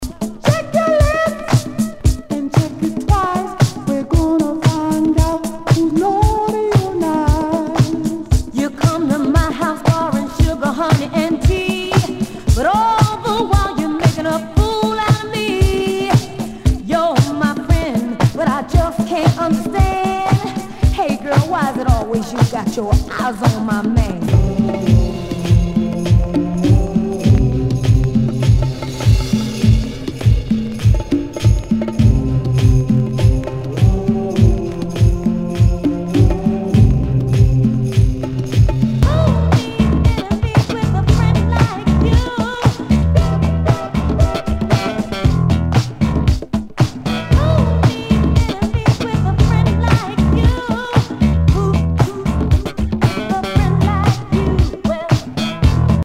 SOUL/FUNK/DISCO
盤に傷あり全体にチリノイズが入ります